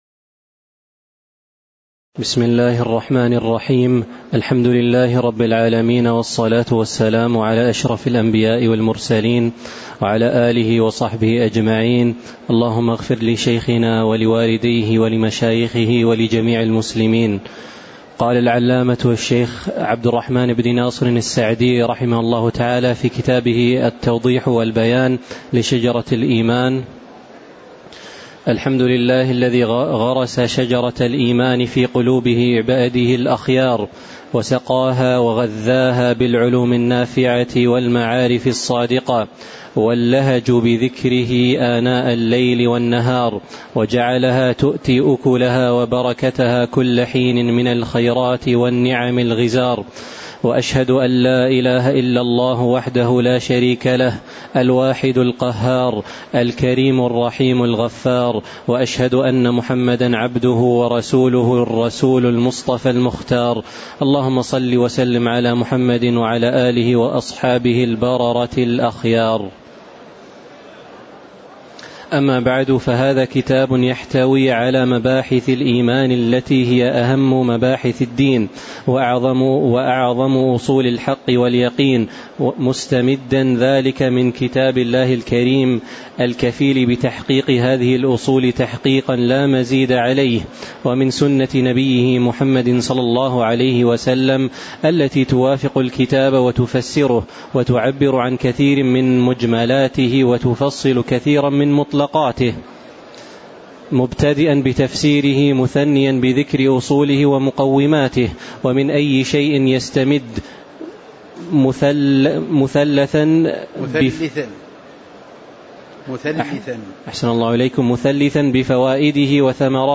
تاريخ النشر ١٤ ربيع الثاني ١٤٤٥ هـ المكان: المسجد النبوي الشيخ